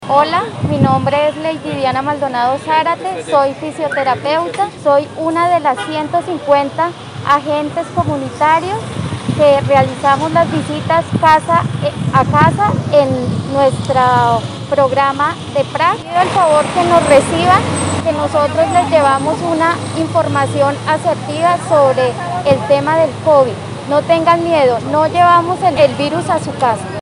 agente comunitario